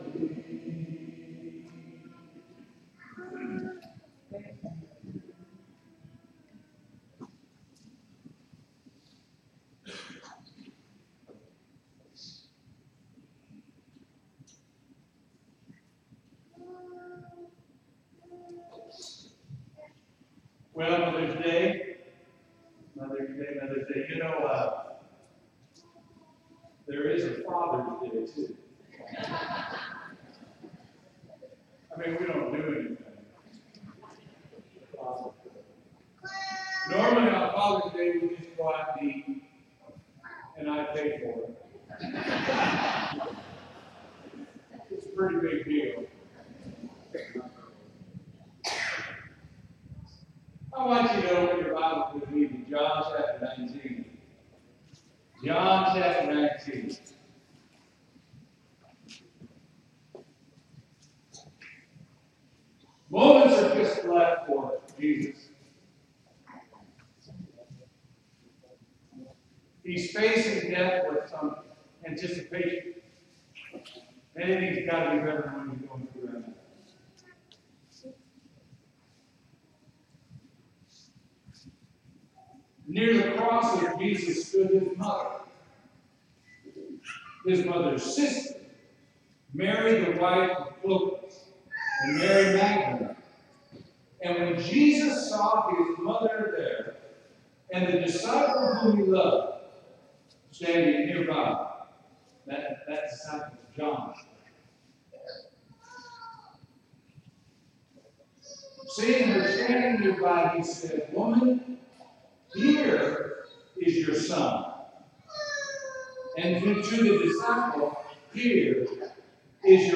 Sermon:
sermon-mothers-day.mp3